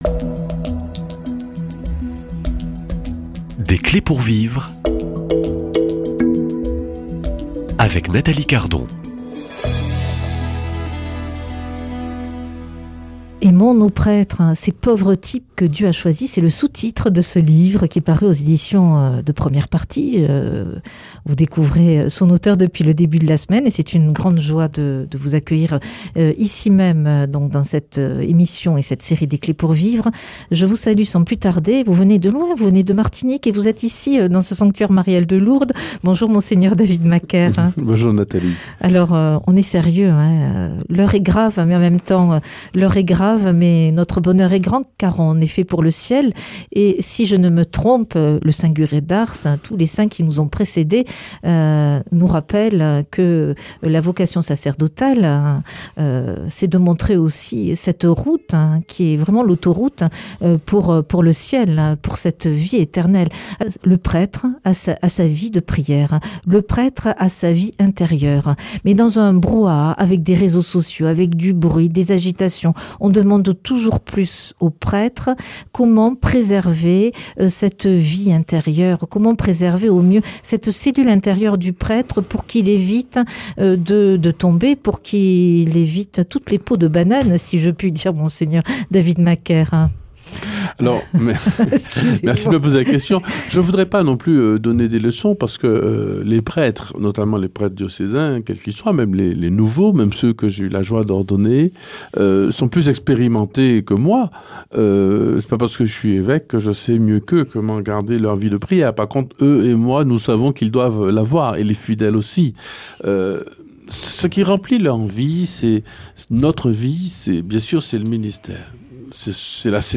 Invité : Mgr David Macaire, ordonné prêtre chez les Dominicains à Toulouse en 2001.